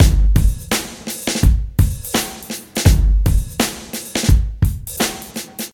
84 Bpm Drum Loop G Key.wav
Free drum loop sample - kick tuned to the G note.
.WAV .MP3 .OGG 0:00 / 0:06 Type Wav Duration 0:06 Size 984,56 KB Samplerate 44100 Hz Bitdepth 16 Channels Stereo Free drum loop sample - kick tuned to the G note.
84-bpm-drum-loop-g-key-nyR.ogg